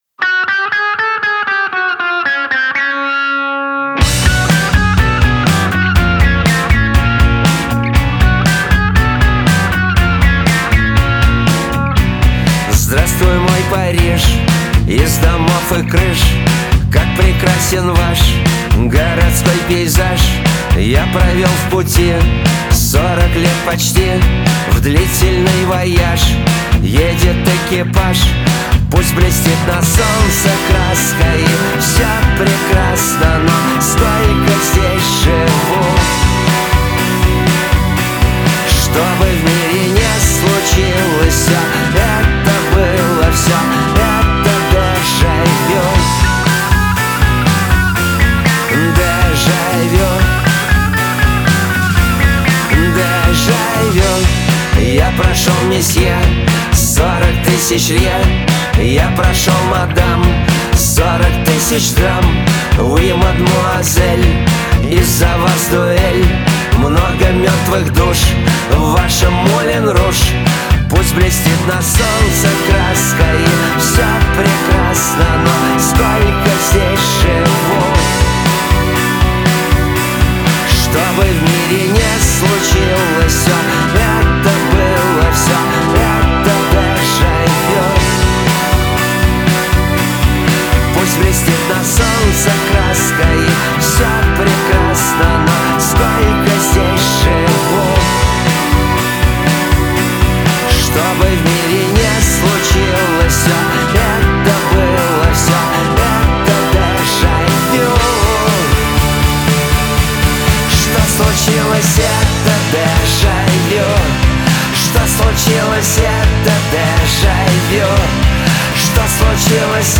Альтернативный рок Инди-рок Электроник-рок